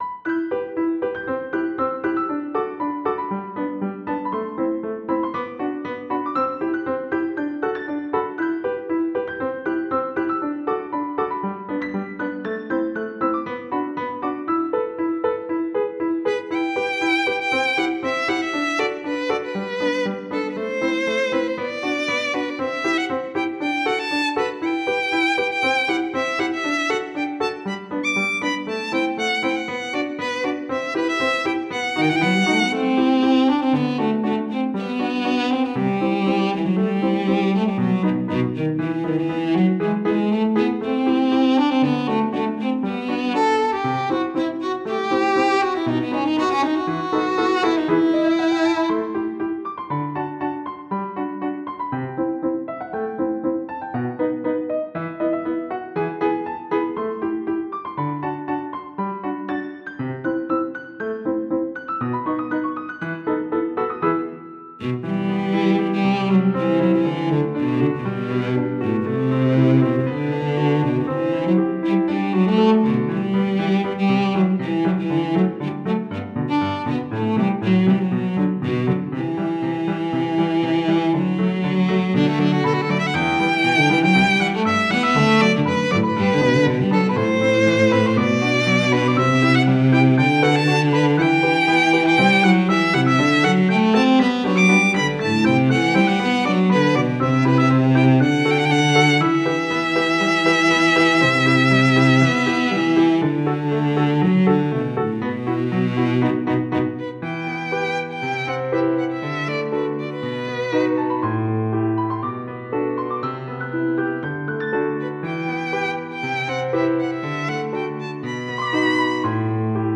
ループ無し（最後に余韻があるバージョン）はこちら